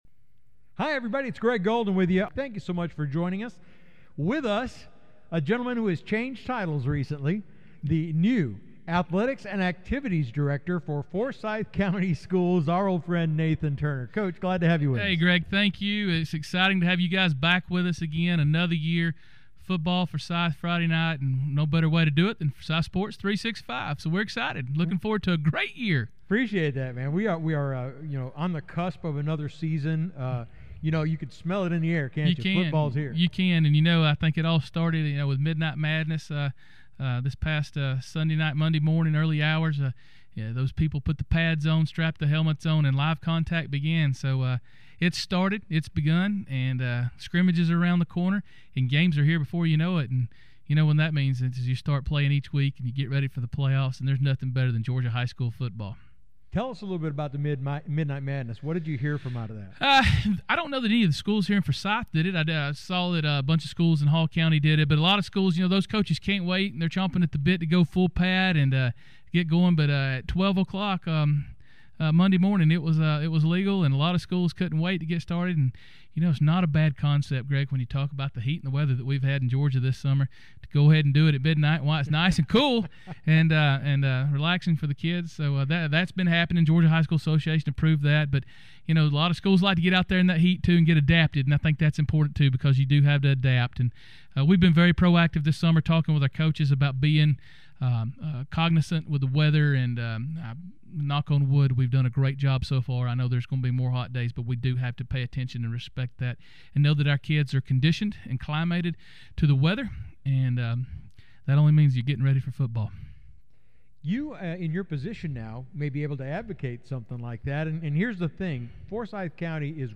Here’s our interview below. Welcome back, football! http